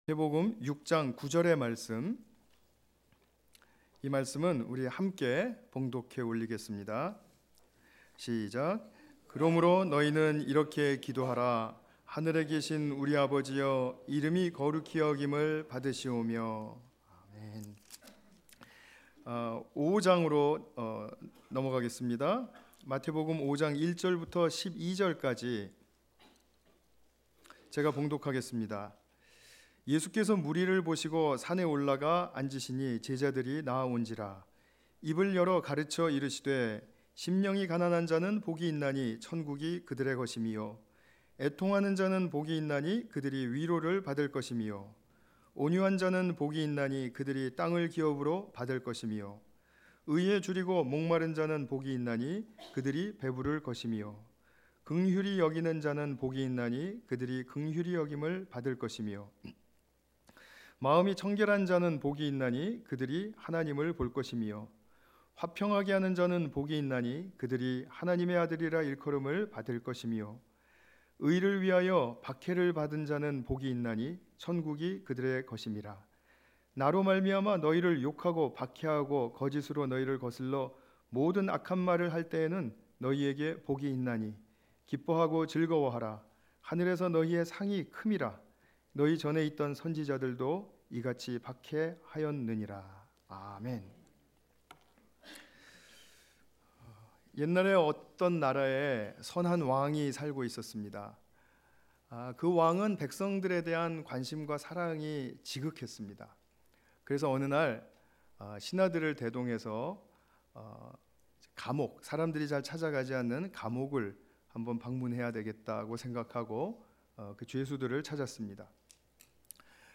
5:1-12 관련 Tagged with 주일예배 Audio